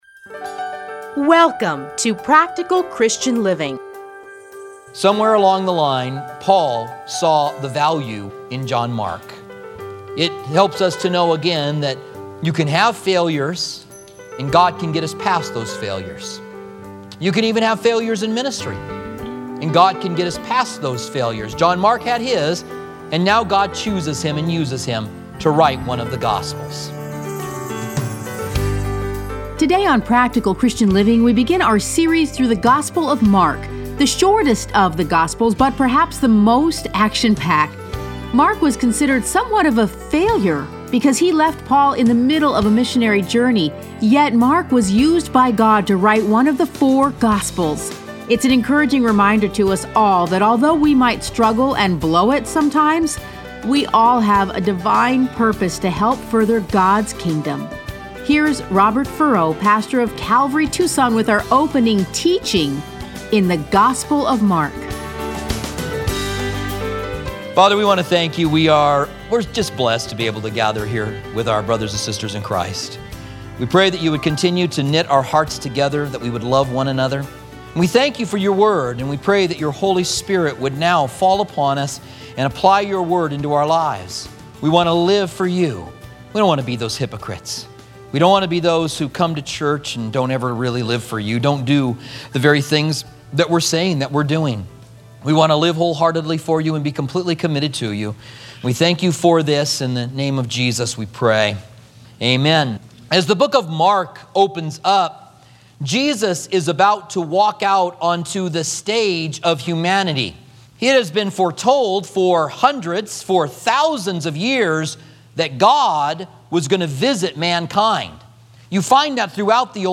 Listen to a teaching from Mark 1:1-8.